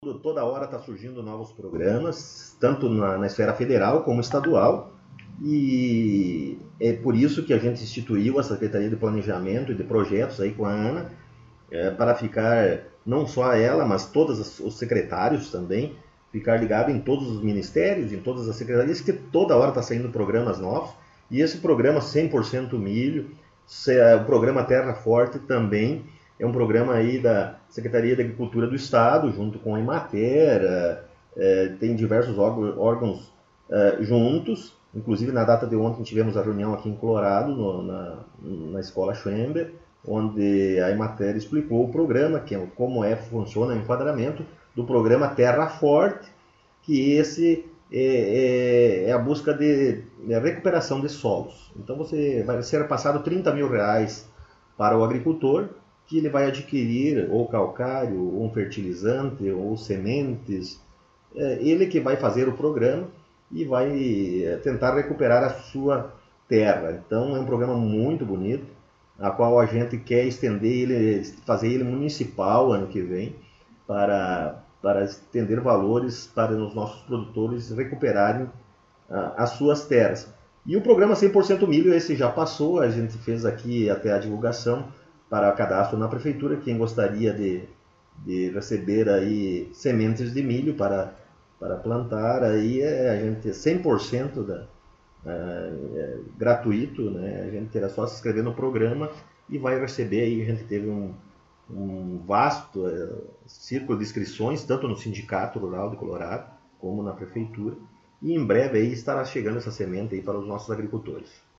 O Colorado em Foco esteve no Gabinete da Prefeitura Municipal para entrevistar o Prefeito Rodrigo e saber das últimas informações de trabalhos, atividades e programas do Governo Municipal.